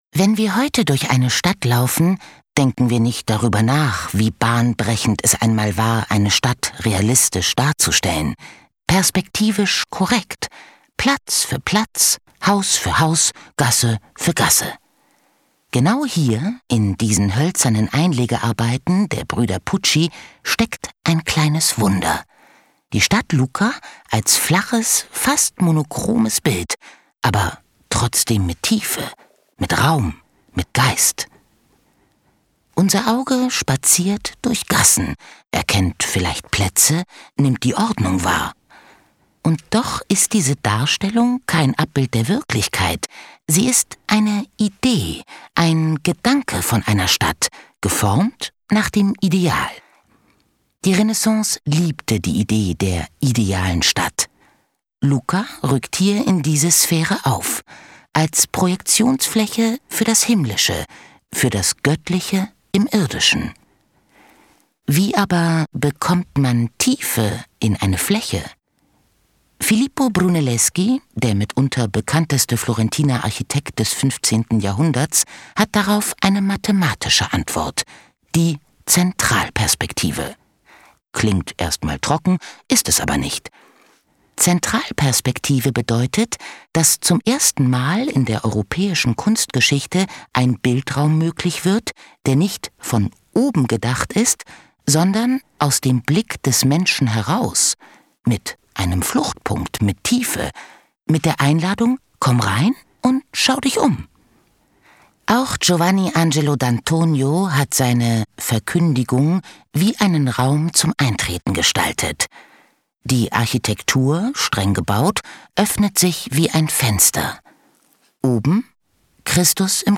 Ausstellung »GÖTTLICH!« im DIMU Freising